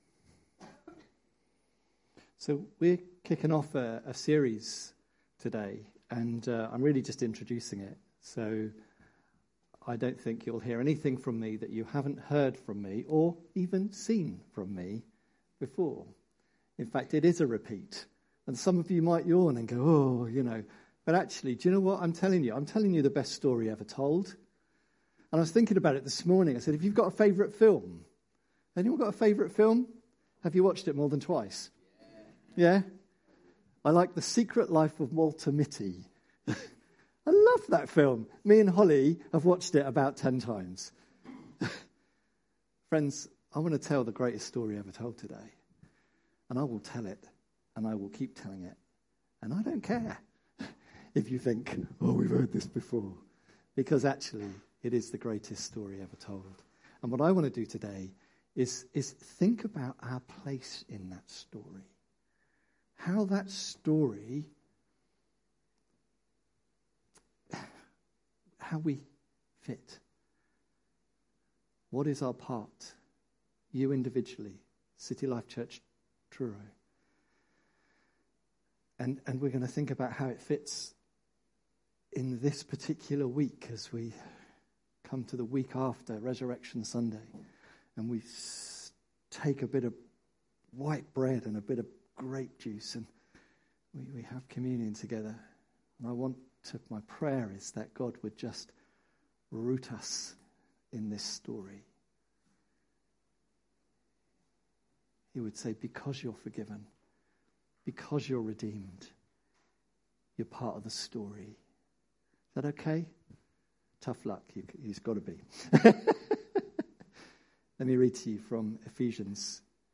12th April – Open Our Eyes – new sermon series